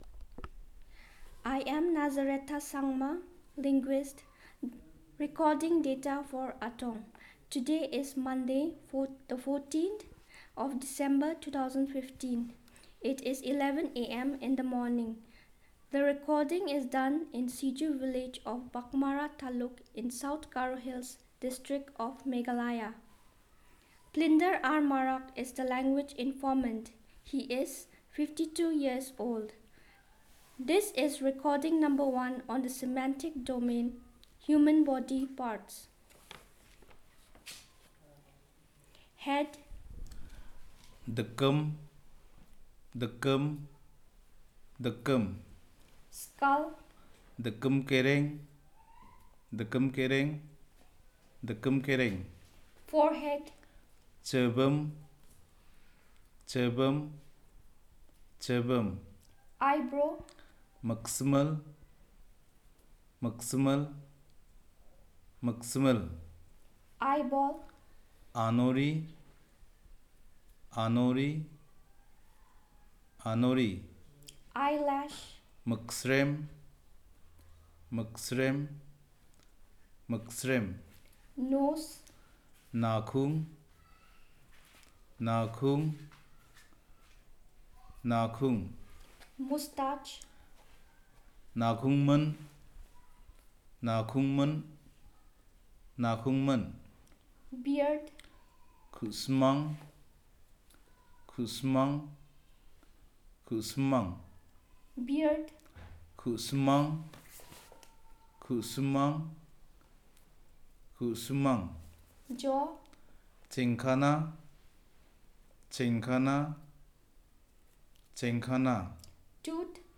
Elicitation of words about human body parts